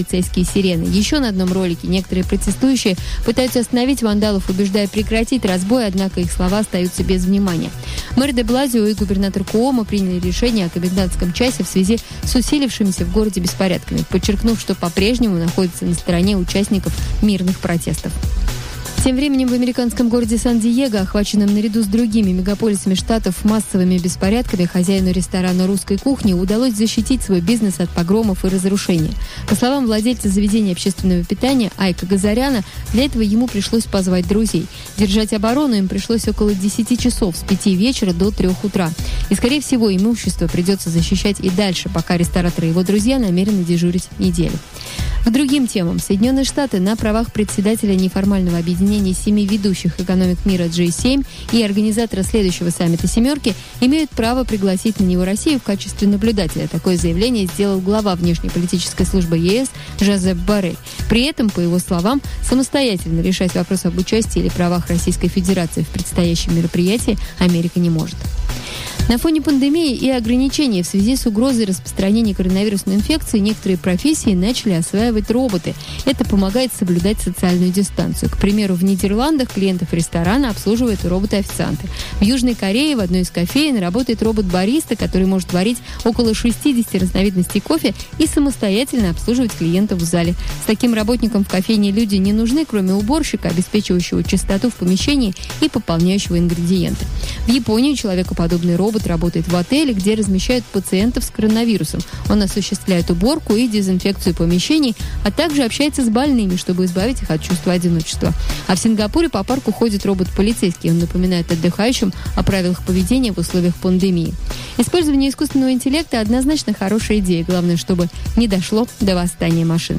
Радио-экскурсии "О. май гид!" на FreedomFM. То, чего вы не знали.